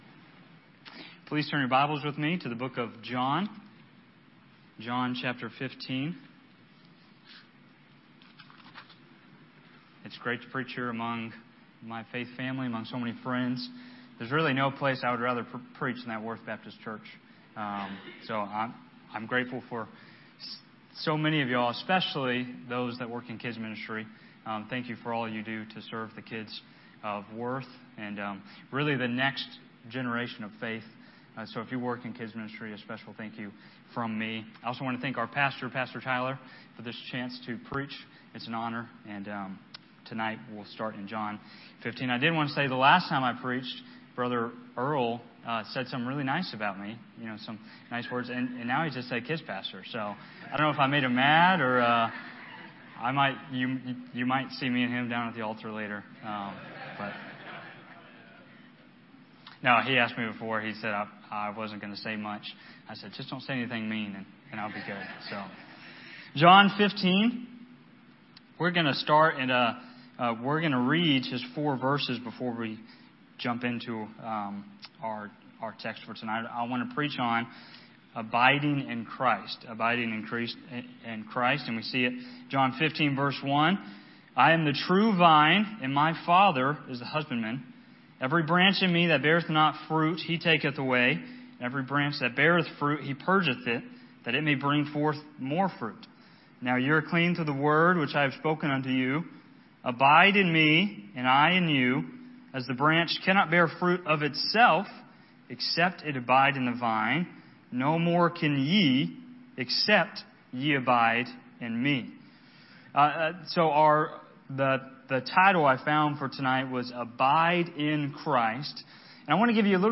Sermons from 2025 - Media of Worth Baptist Church